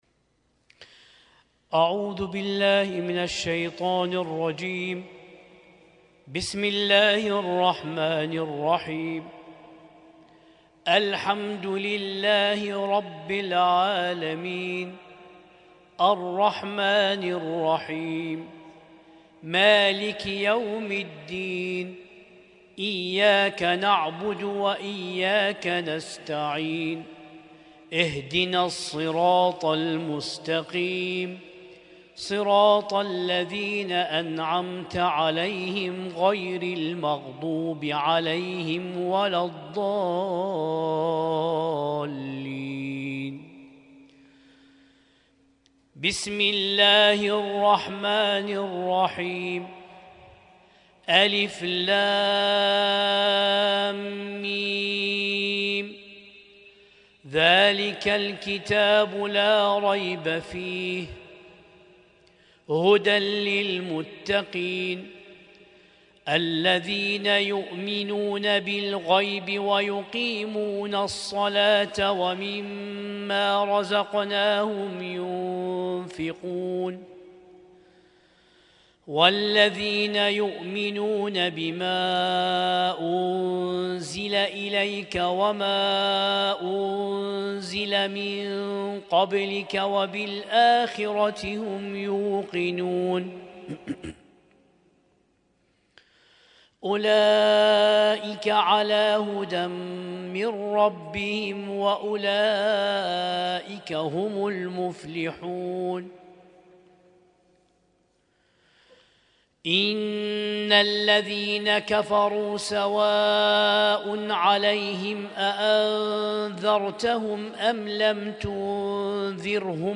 اسم التصنيف: المـكتبة الصــوتيه >> القرآن الكريم >> القرآن الكريم 1447